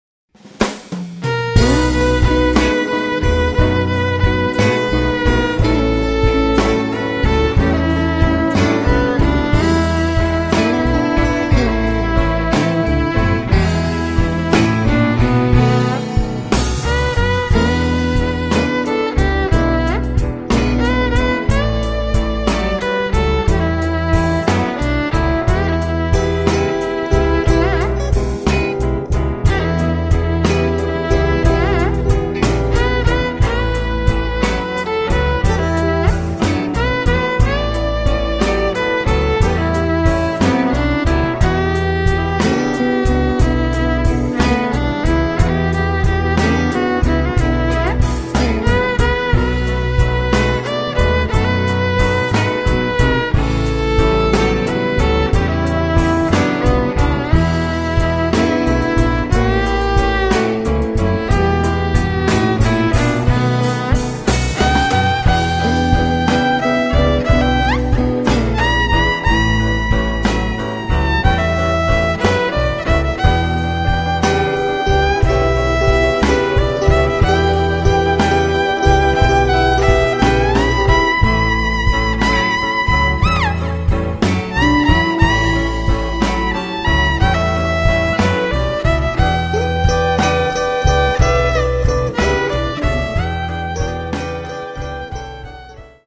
The repertoire covers a wide range of blues styles
instrumental pieces
soulful, expressive violin playing